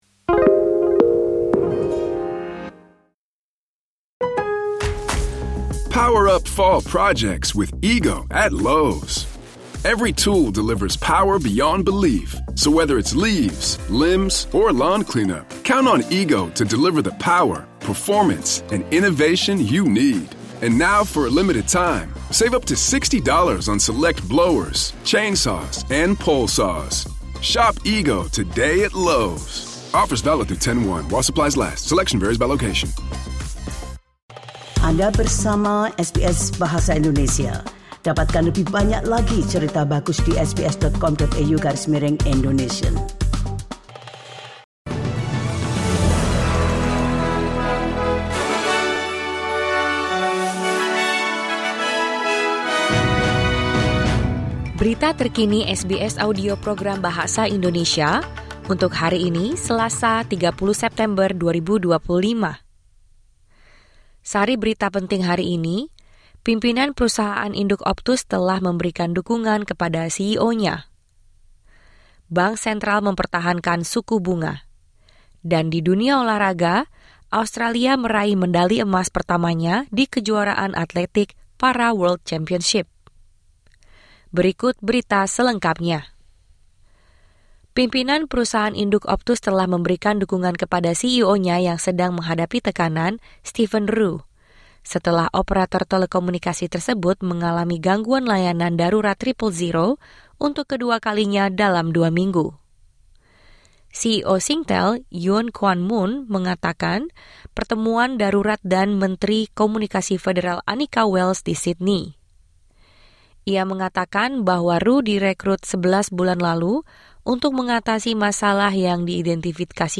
Berita Terkini SBS Audio Program Bahasa Indonesia – 30 September 2025